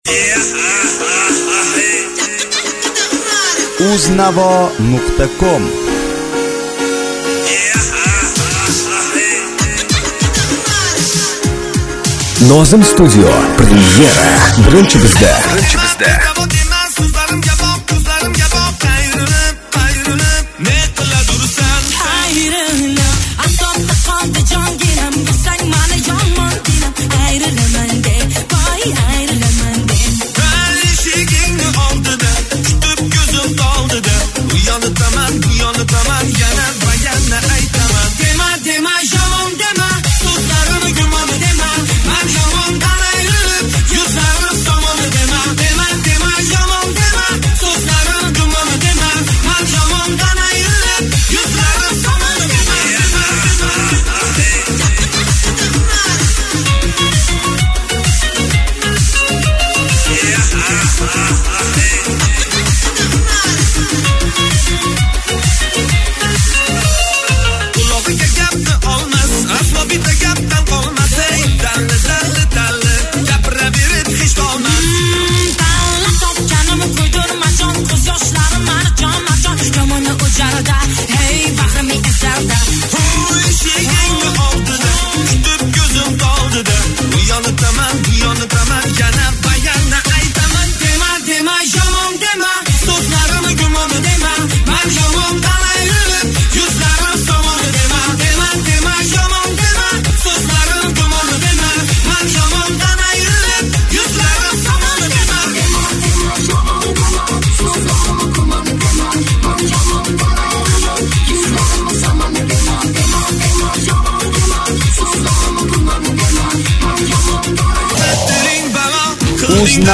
UZBEK MUSIC [7816]
minus